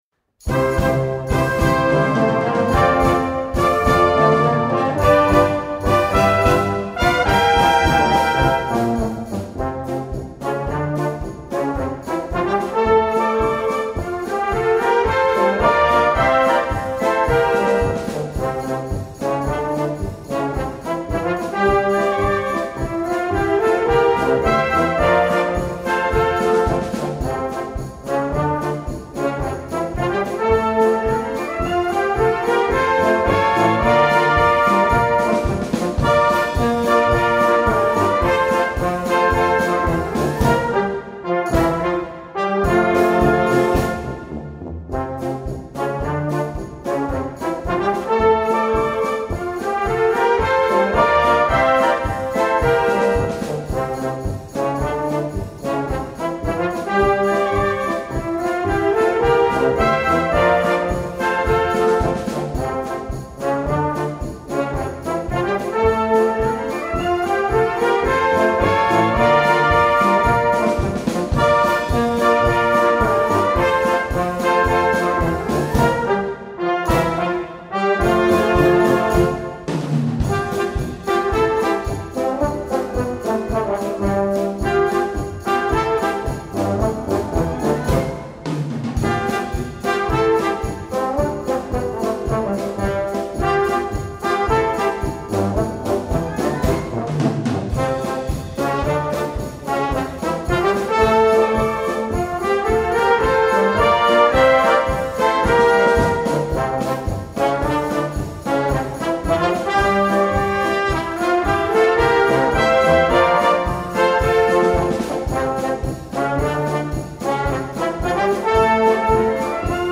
Gattung: Beat-Polka
Besetzung: Blasorchester